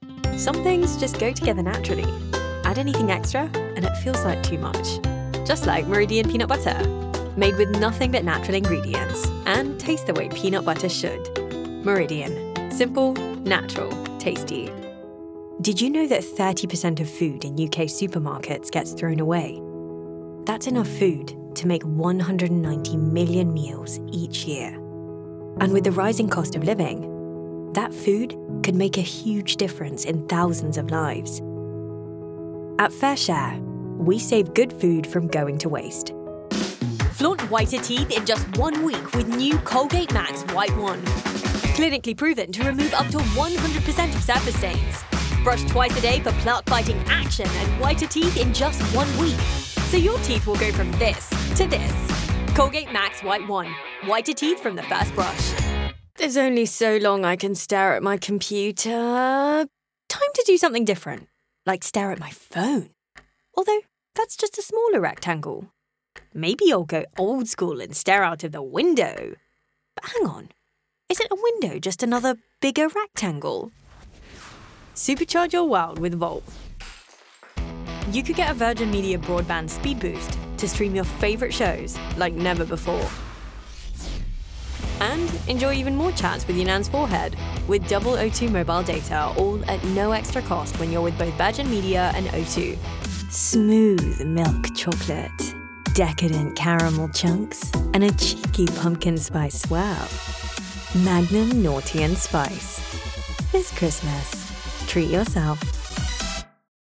Commercial Demo - British
Vocal qualities: Conversational, believable, friendly, warm, upbeat, cool, natural, engaging, relatable, confident, professional.